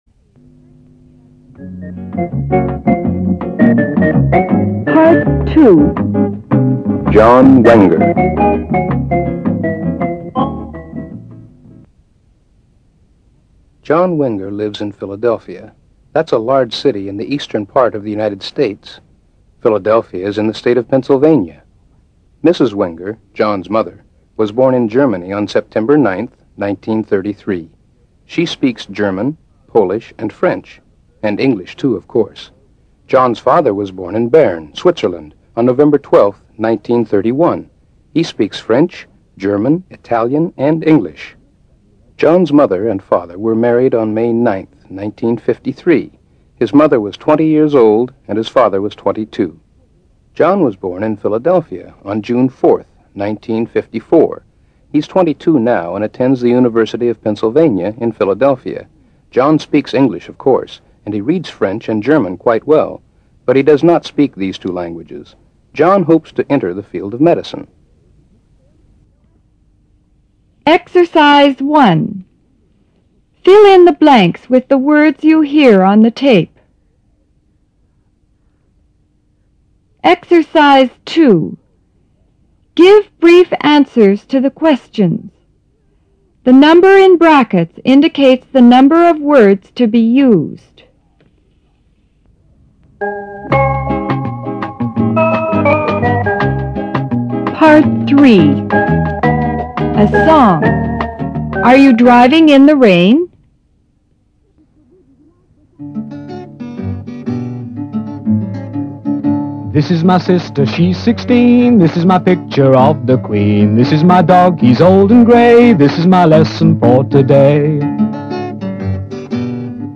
Part Ⅲ A Song----Are You Driving In The Rain？